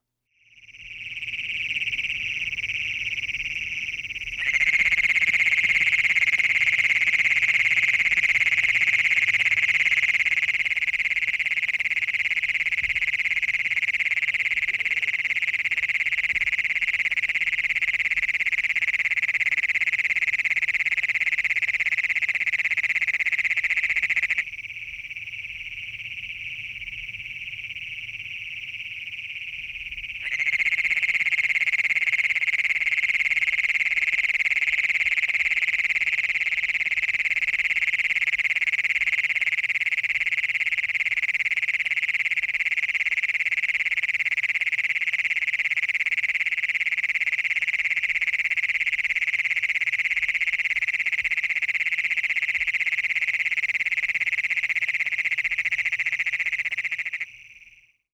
Locality near Wilcox
State Arizona
Call Type advertisement
Anaxyrus_cognatus.wav